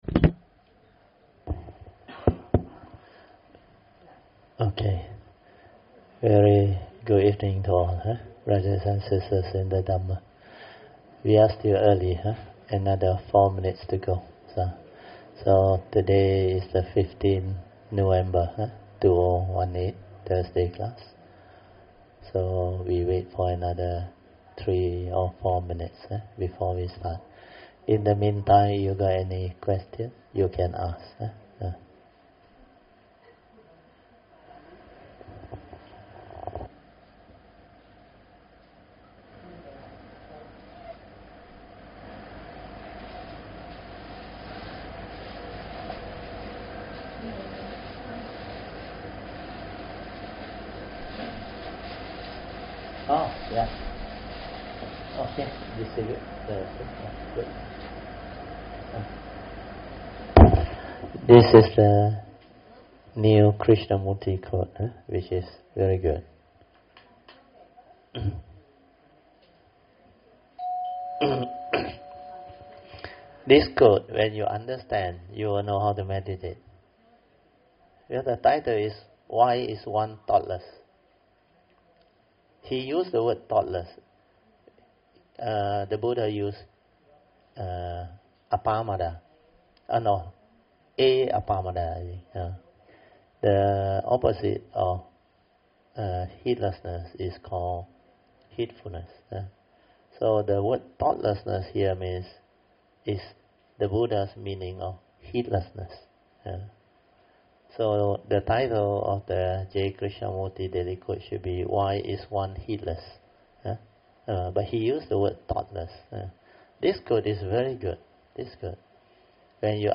Thursday Class